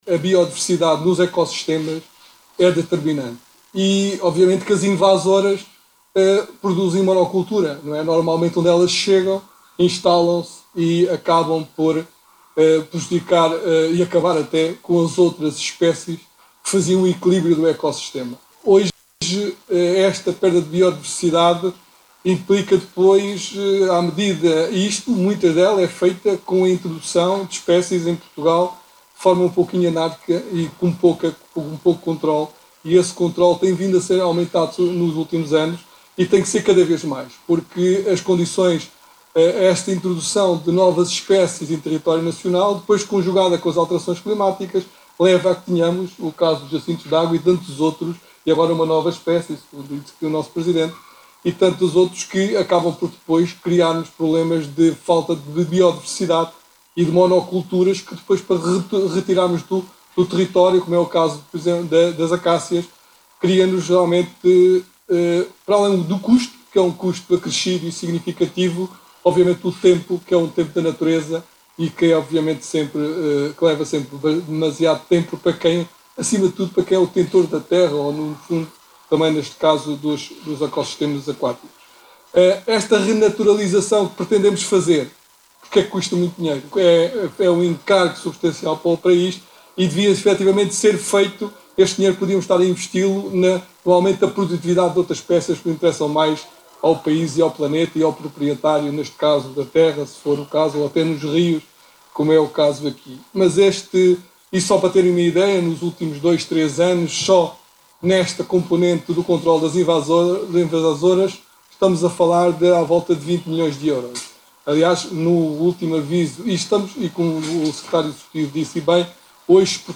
Portugal disponibilizou 20 milhões de euros nos últimos três anos para controlo e erradicação de espécies invasoras aquáticas e terrestres no território nacional, disse hoje, em Montemor-o-Velho, no distrito de Coimbra, o secretário de Estado das Florestas.
Oiça aqui o discurso do secretário de estado da Conservação da Natureza, das Florestas e do Ordenamento do Território, João Catarino.